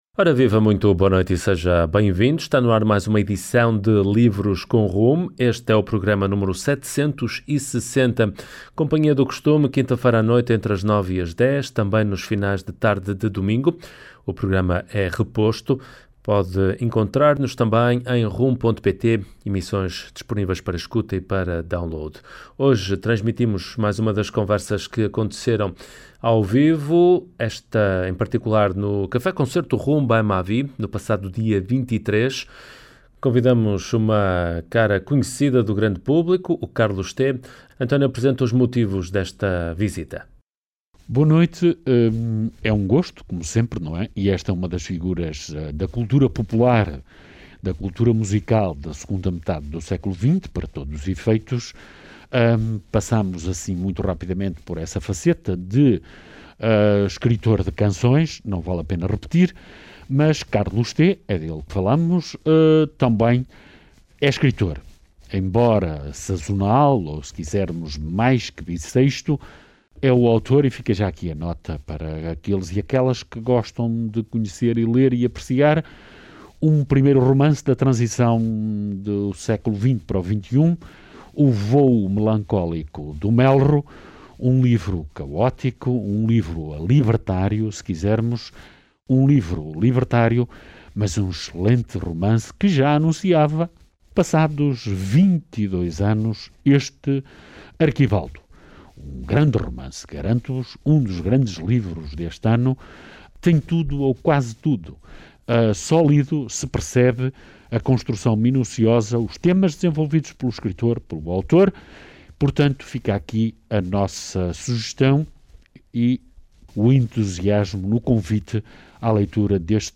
Conversa com Carlos Tê